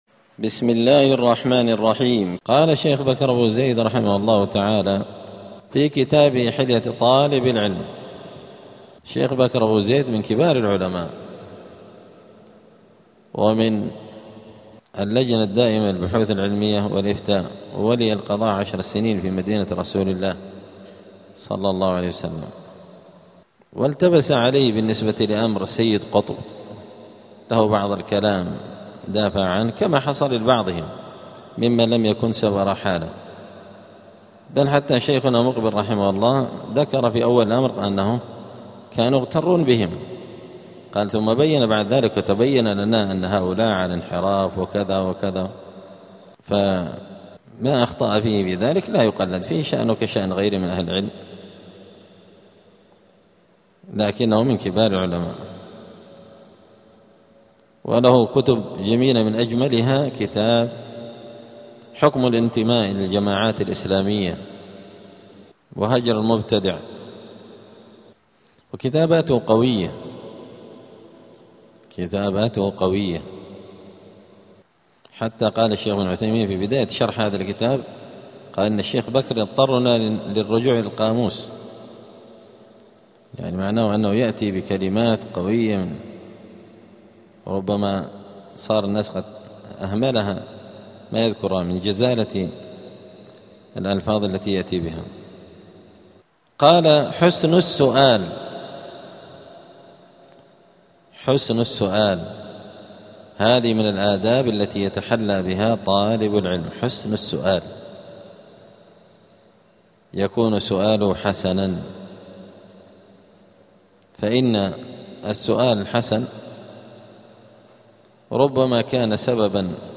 *الدرس الثاني والثمانون (82) فصل آداب الطالب في حياته العلمية {حسن السؤال}.*
دار الحديث السلفية بمسجد الفرقان قشن المهرة اليمن